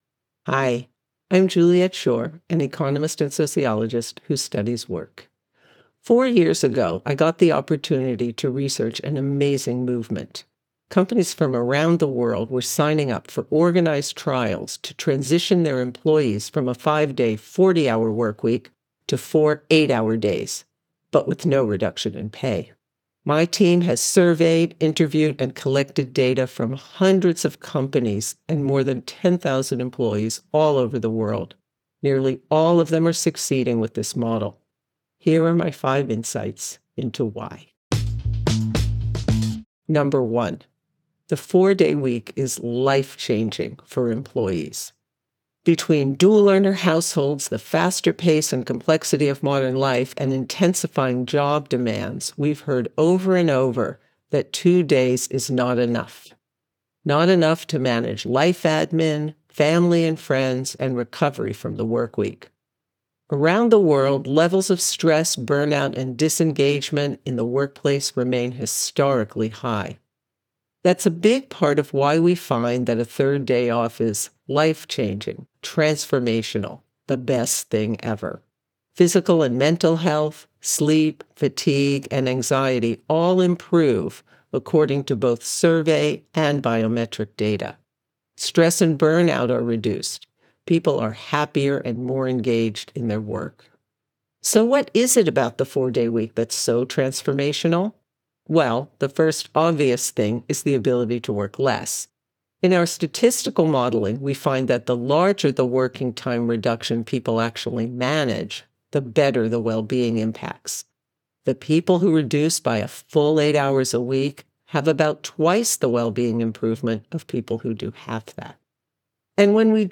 Listen to the audio version—read by Juliet herself—in the Next Big Idea App.